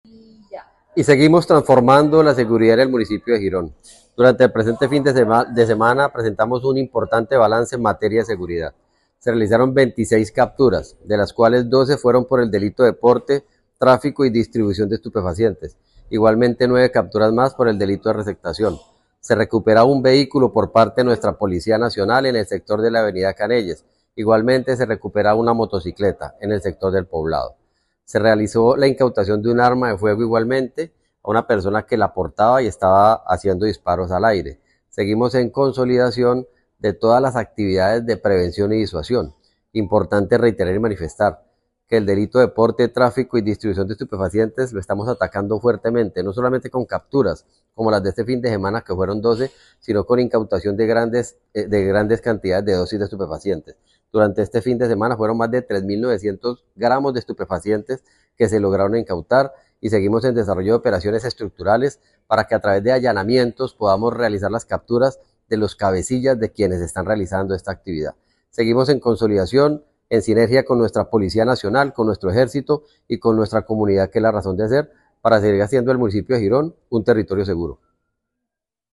Secretario de Seguridad Juan Carlos Pinto.mp3